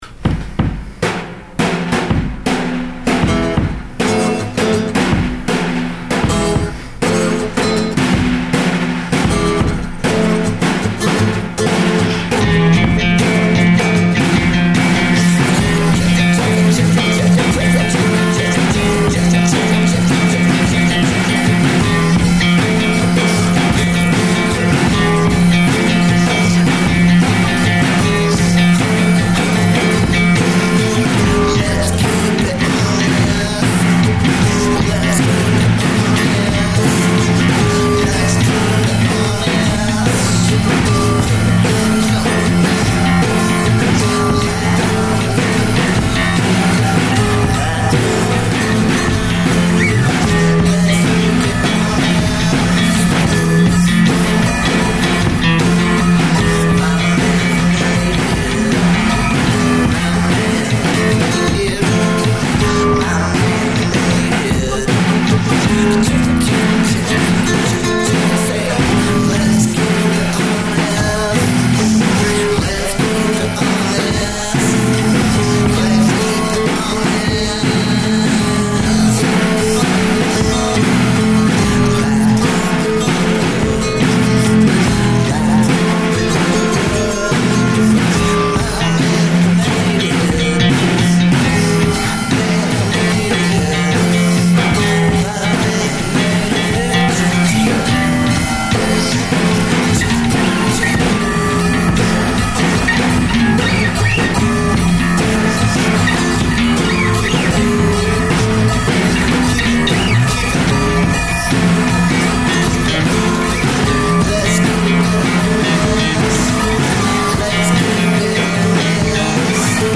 Everybody overseas WAKE UP to some HOME-BREWED FUNK!